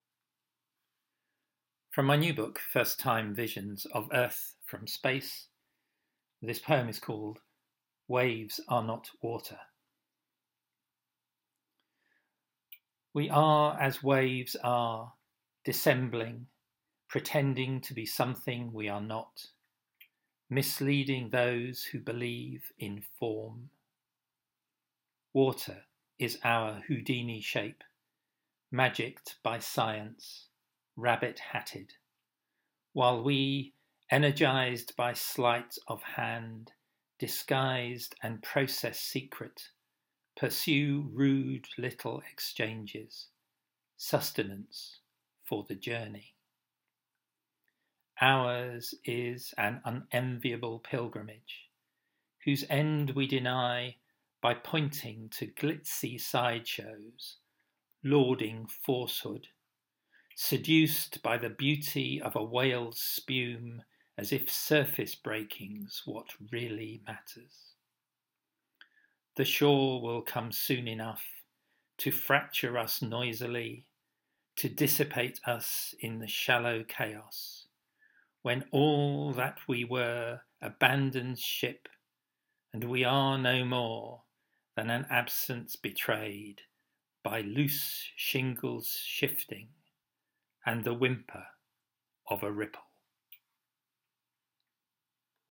You may need to turn the volume up on your machine – and I may need to invest in a proper microphone!